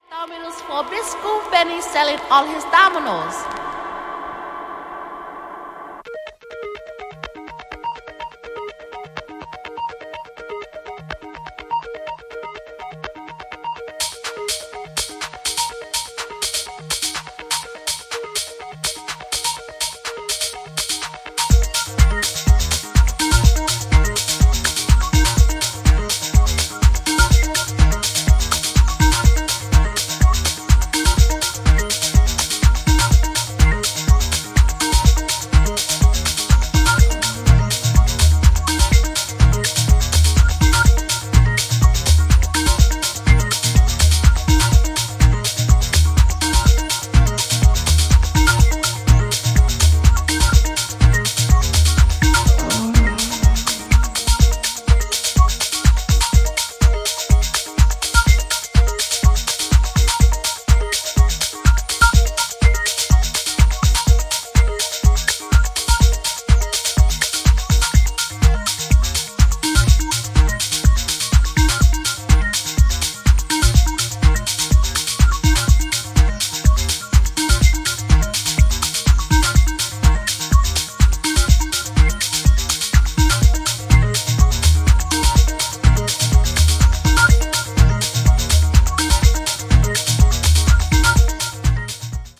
NYよりも当時のUK/EUっぽい空気感が色濃く感じられてきますね。